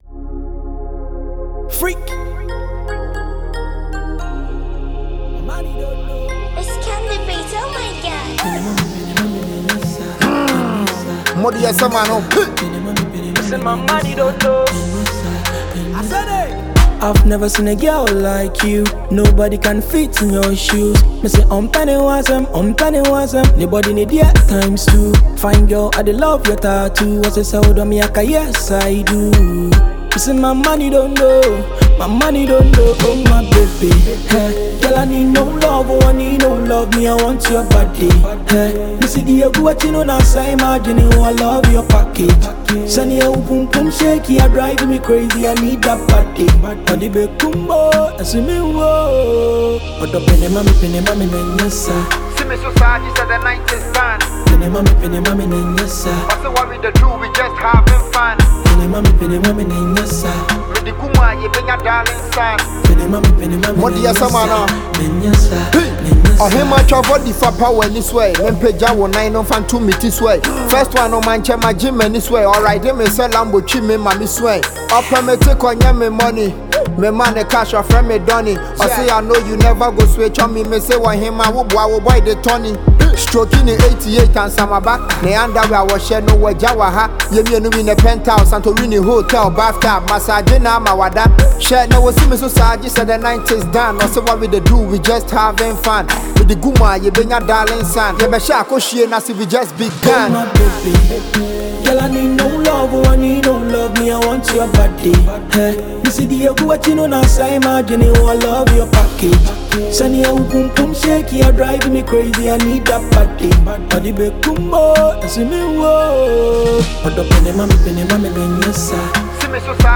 Check out this new tune from Ghanaian rapper